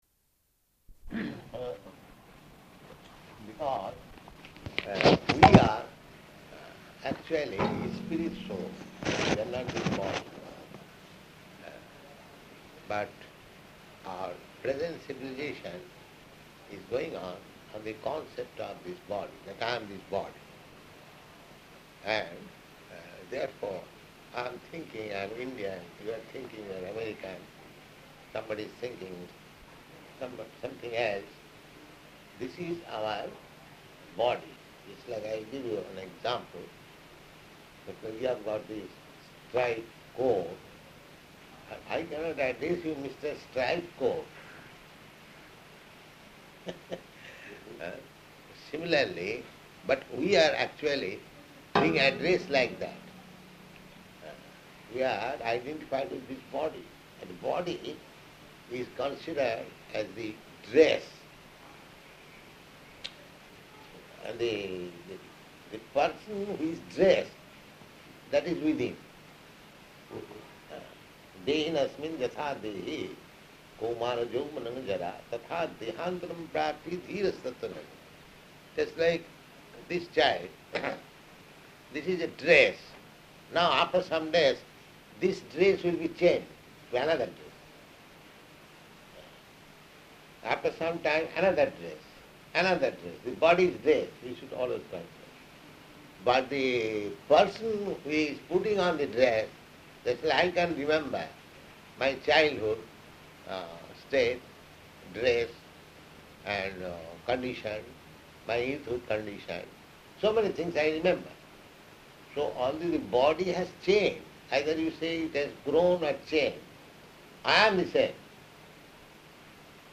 -- Type: Conversation Dated: October 14th 1972 Location: Delhi Audio file